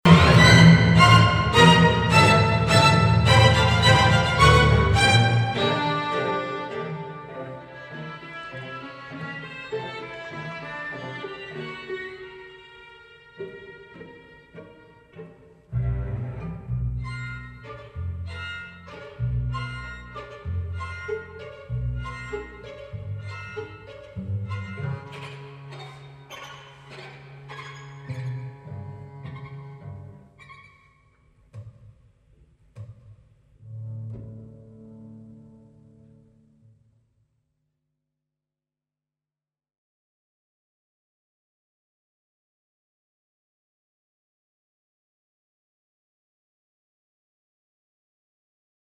soprano